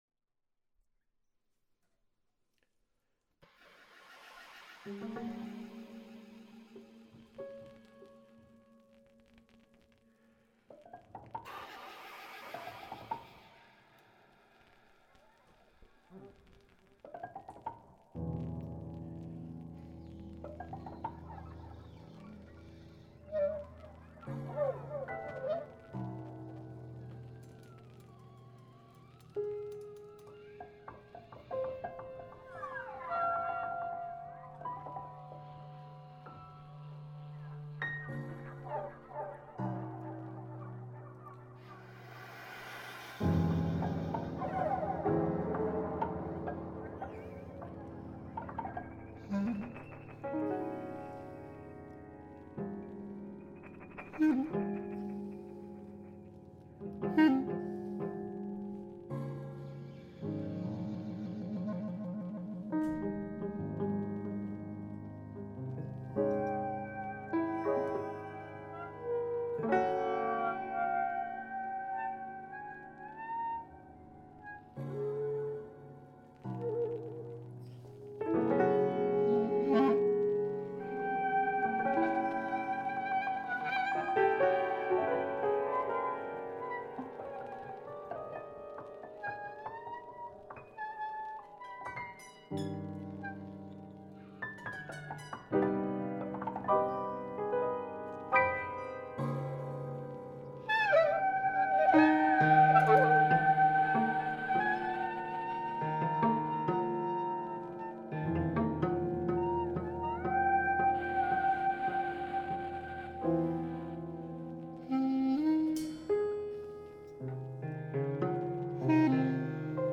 Concert
piano